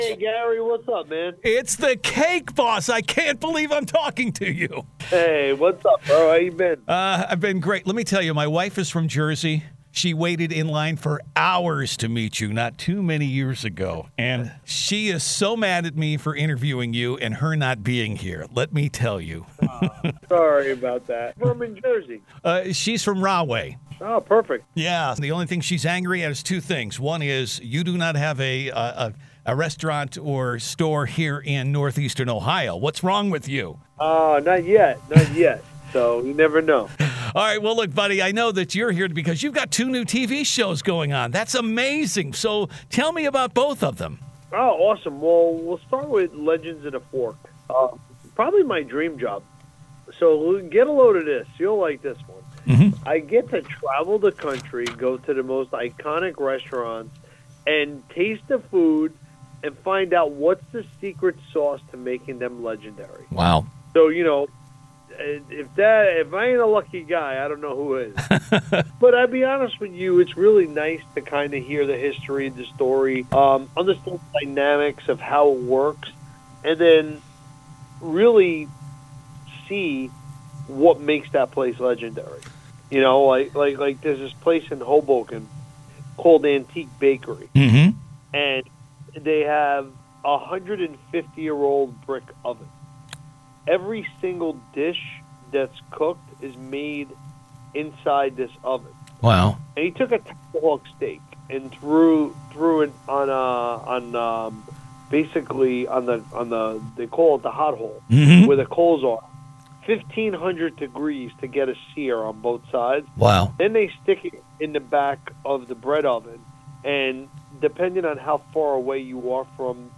CAKE BOSS INTERVIEW